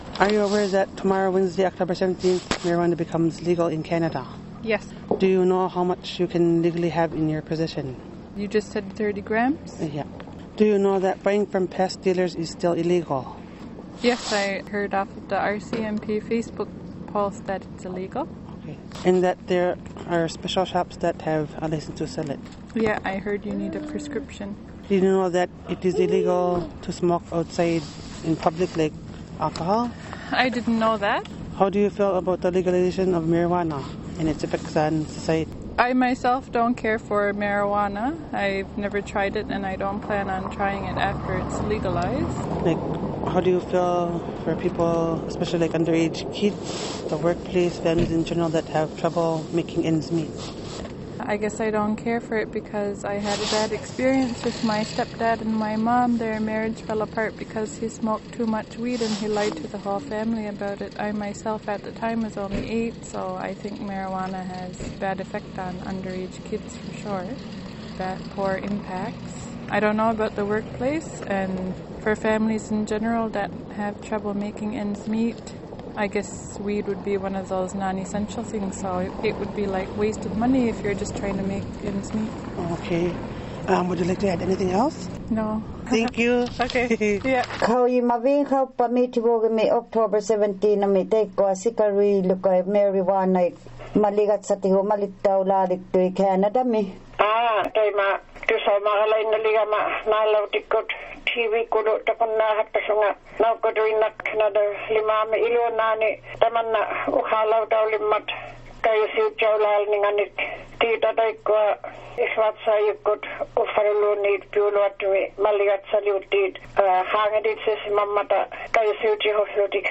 Two of our reporters hit the streets today to get peoples take on this.
Out of the many asked only three agreed to answer our questions on the Legalization of Marijuana.